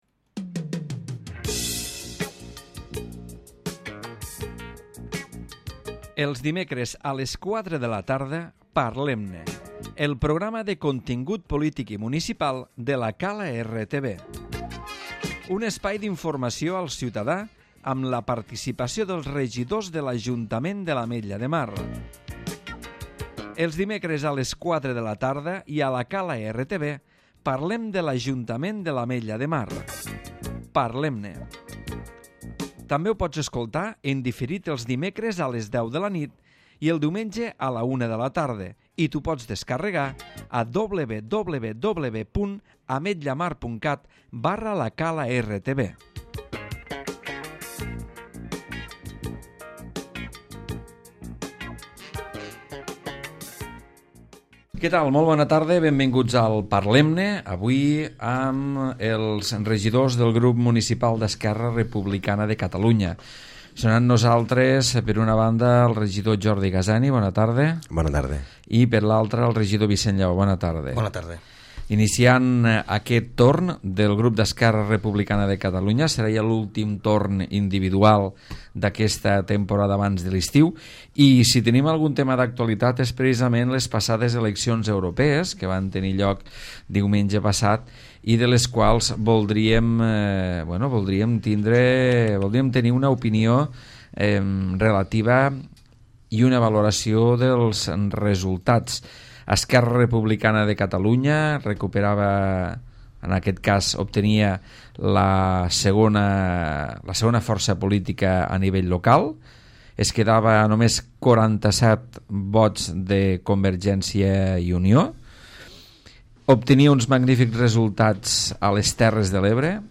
Jordi Gaseni i Vicent Llaó, regidors del Grup Municipal d'ERC a l'Ajuntament de l'Ametlla de Mar analitzen l'actualitat municipal al Parlem-ne.